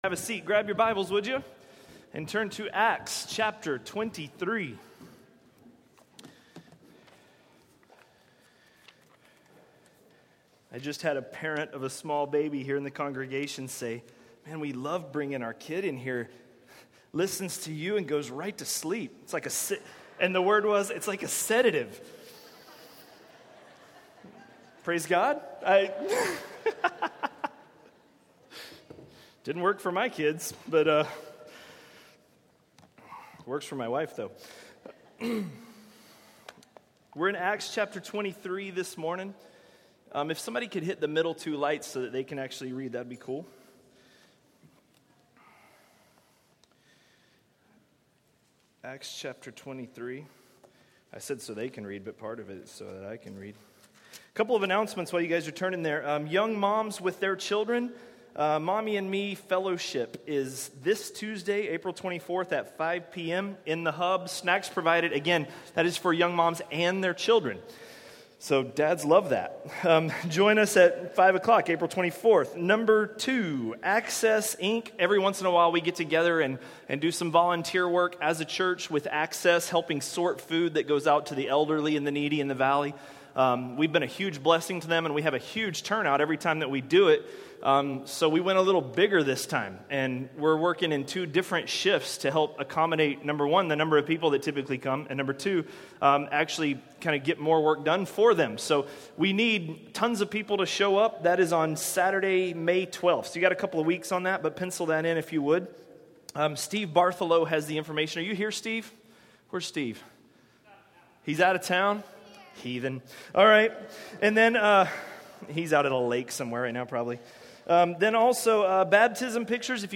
A message from the series "Acts." Acts 23–24